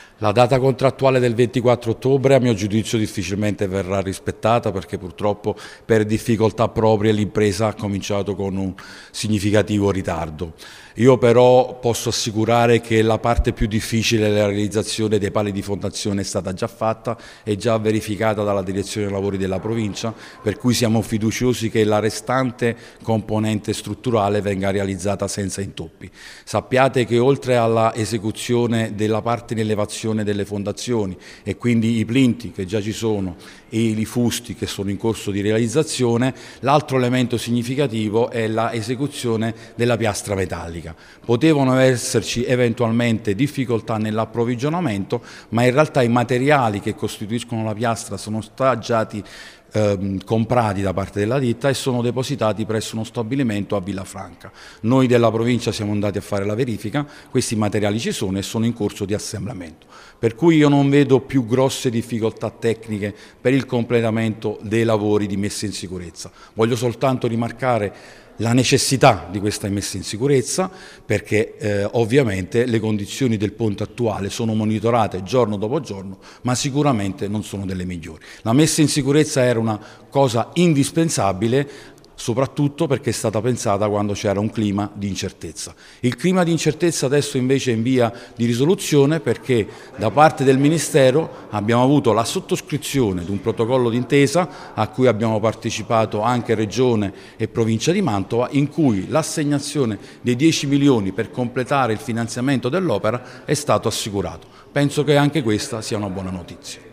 Ecco le dichiarazioni raccolte nella giornata dell’incontro: